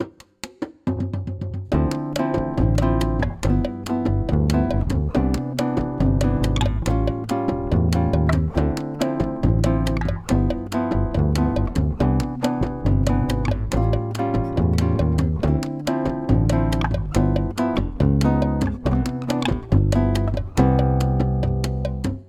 Apumateriaaliksi nauhoitin kymmenen toistoa siten, että muusikkoa säestävät harmoniset ja rytmiset instrumentit. Kaikki toistot äänitettiin kotistudiossani.